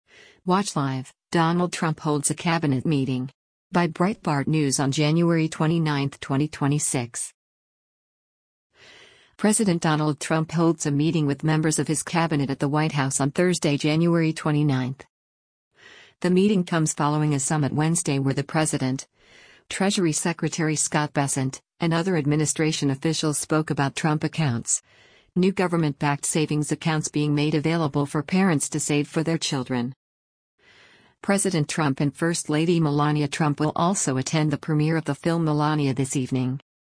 President Donald Trump holds a meeting with members of his cabinet at the White House on Thursday, January 29.